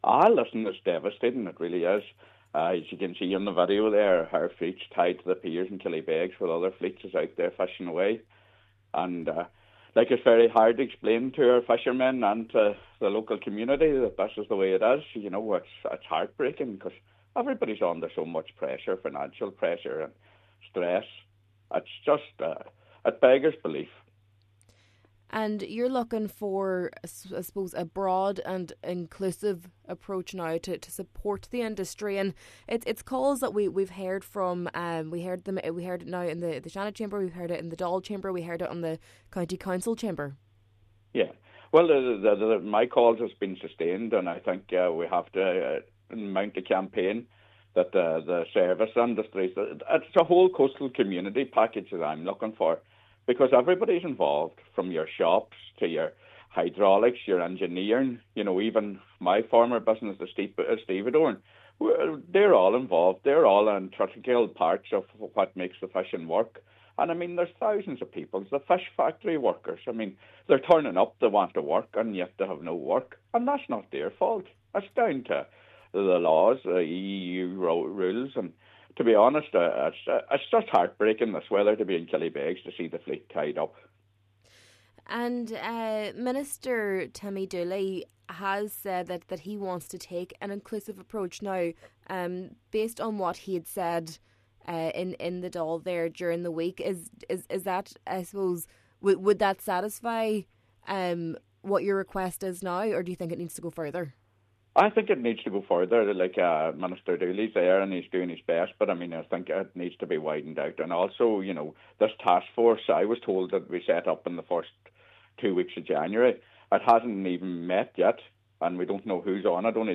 Senator Manus Boyle says he understands it was meant to be operational at the start of the year: